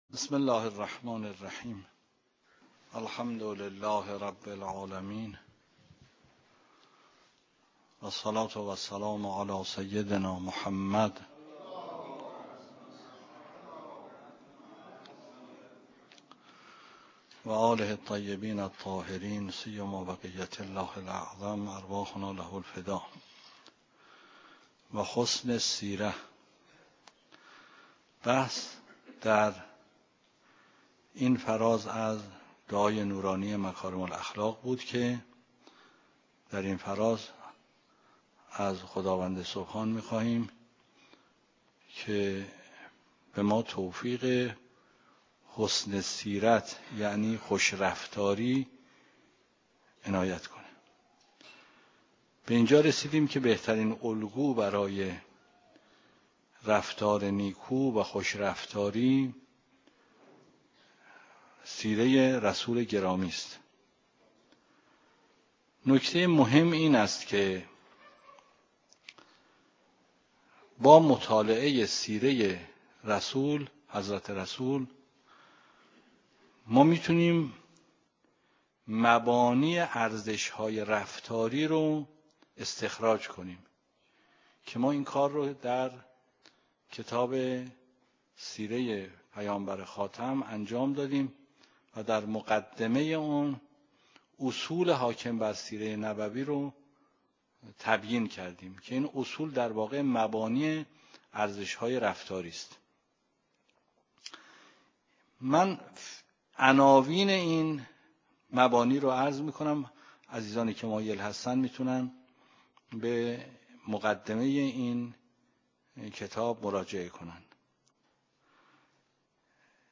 درس خارج فقه مبحث حج